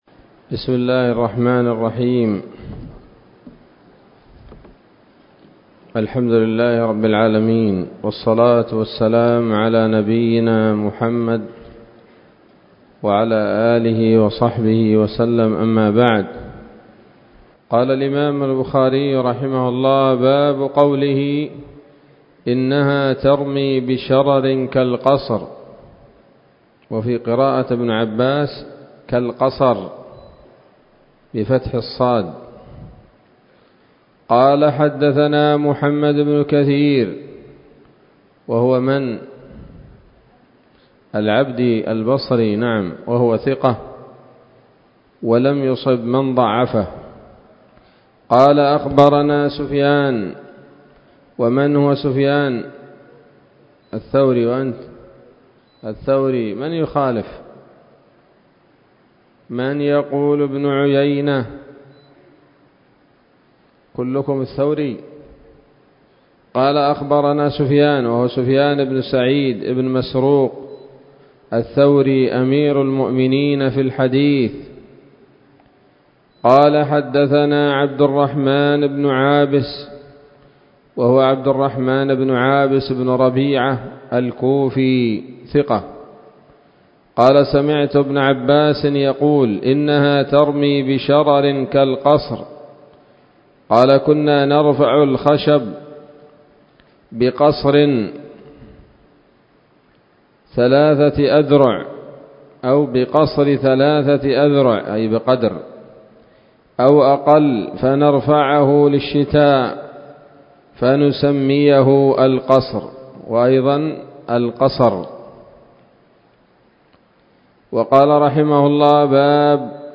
الدرس التاسع والسبعون بعد المائتين من كتاب التفسير من صحيح الإمام البخاري